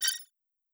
SciFiNotification2.wav